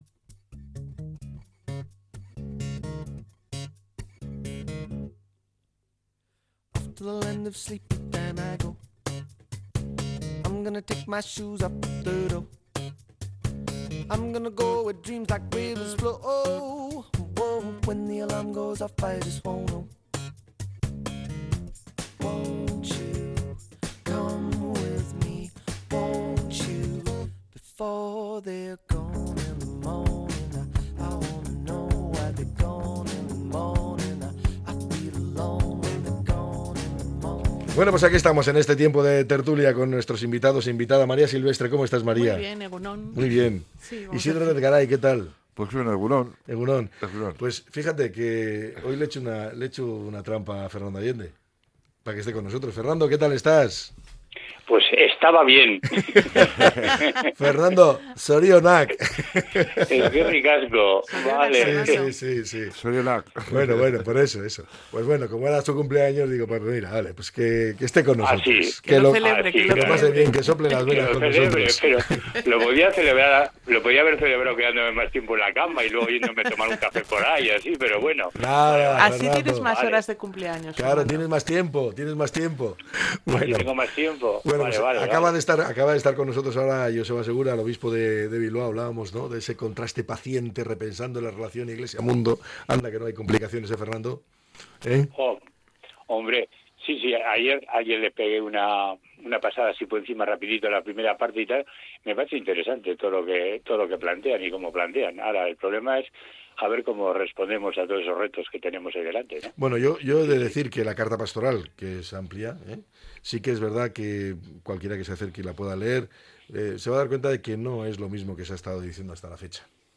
La tertulia 05-03-25.